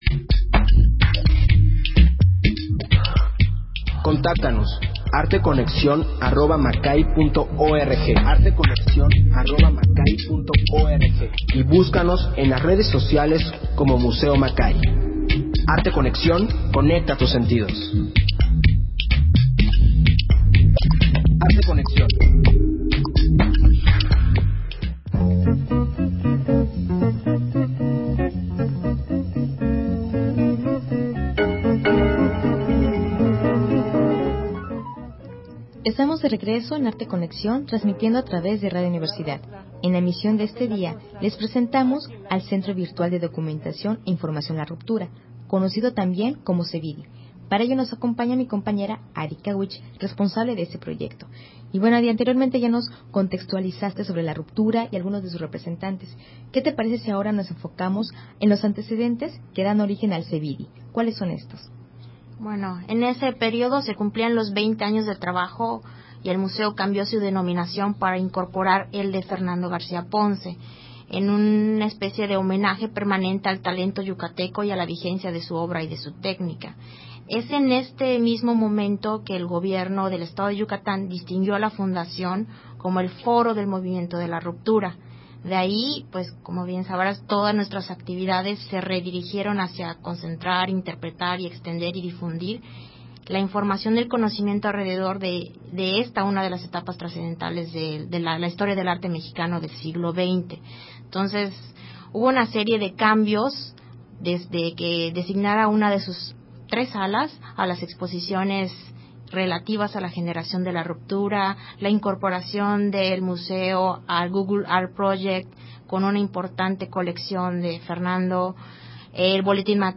Emisión de Arte Conexión transmitida el 4 de febrero.